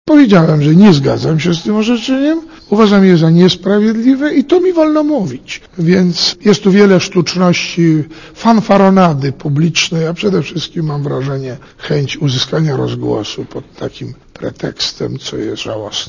mówi Józef Oleksy